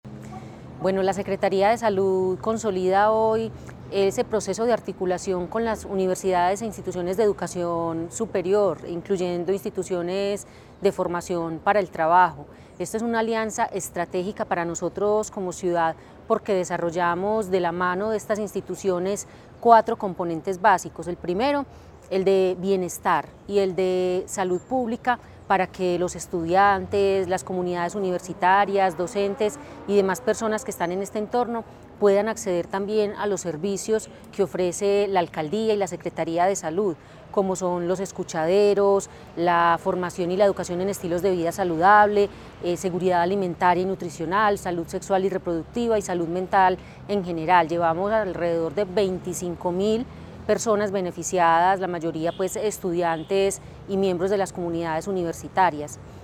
Declaraciones-de-la-secretaria-de-Salud-Natalia-Lopez-Delgado.mp3